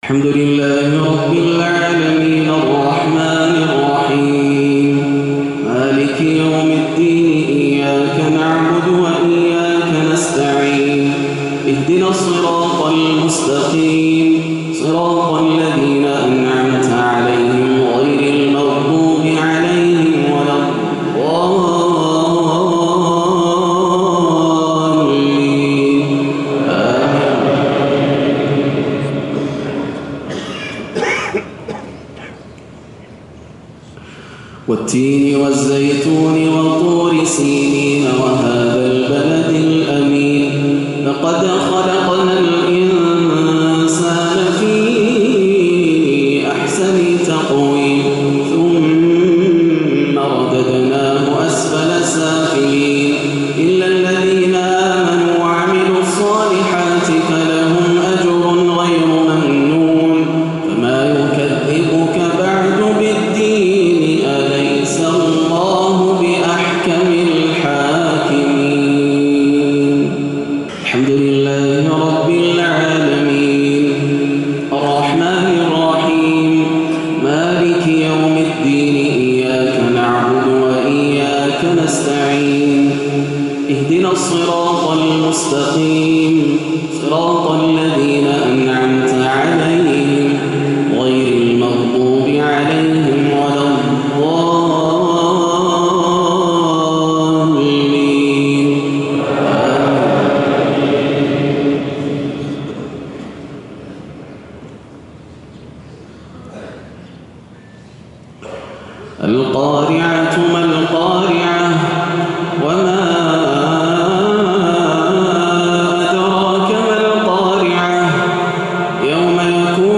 صلاة الجمعة 7-3-1437هـ سورتي التين و القارعة > عام 1437 > الفروض - تلاوات ياسر الدوسري